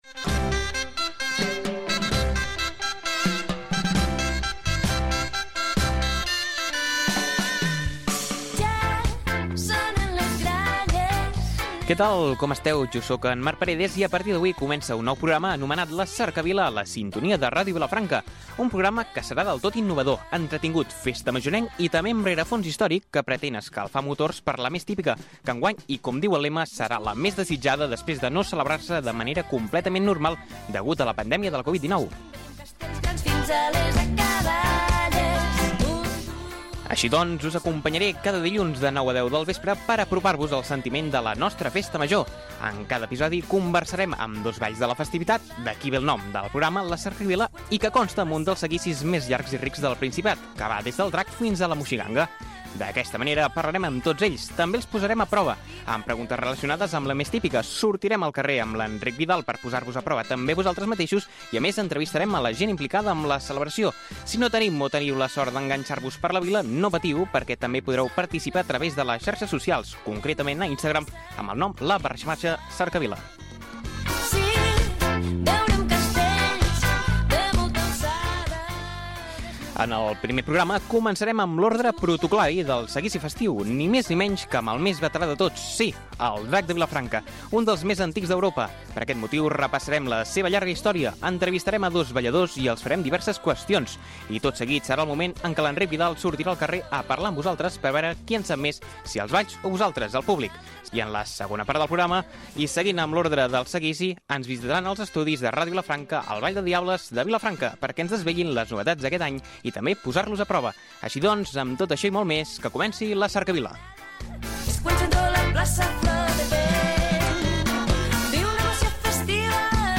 Cultura
FM